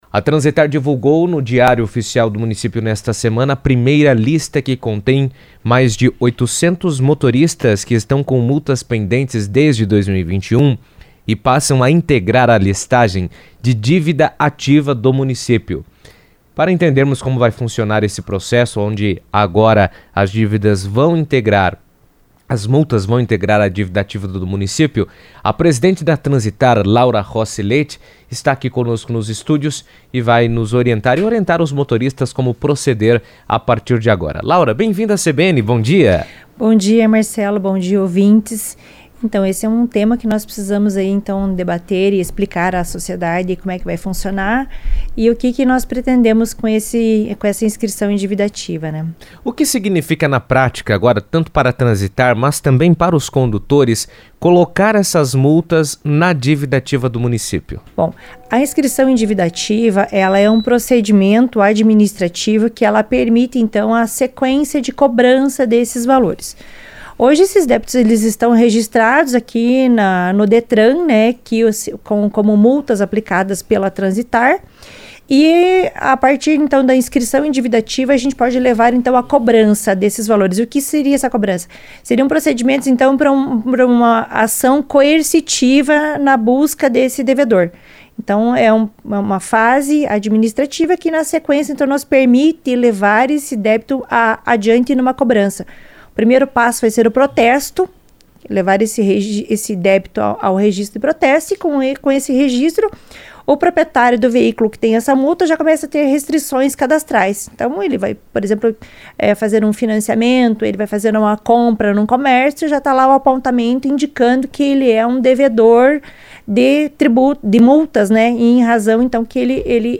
Pela primeira vez, a Transitar incluiu na dívida ativa do município os nomes de mais de 800 motoristas com multas pendentes. A lista reúne infrações que somam débitos e visa incentivar a regularização das pendências. Laura Rossi Leite, presidente da Transitar, comentou sobre a iniciativa em entrevista à CBN, destacando a importância da medida para a organização e o cumprimento das normas de trânsito.